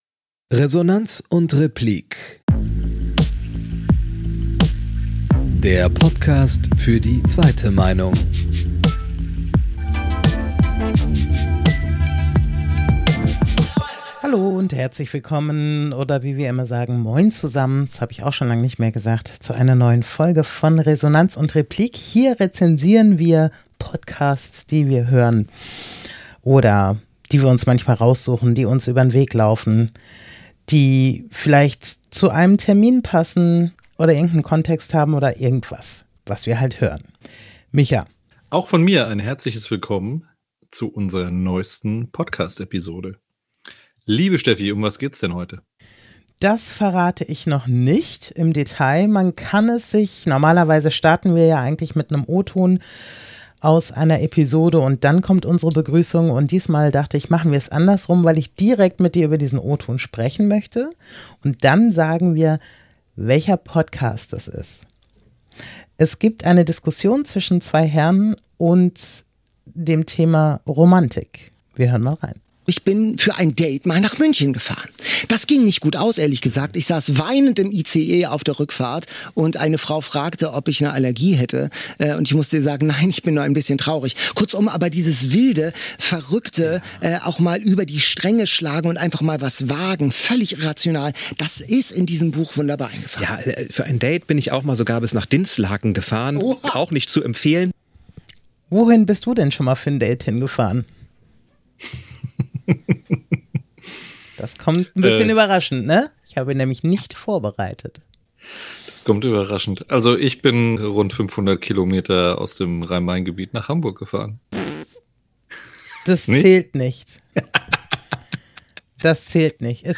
Bei "Eat, Read, Sleep"vom NDR diskutieren sie die Themen Romantik und Literatur, die in der neuesten Folge. Mit einem humorvollen O-Ton starten sie in die Episode, der die Hörer auf die Spontaneität und das verrückte Wesen von romantischen Dates einstimmt.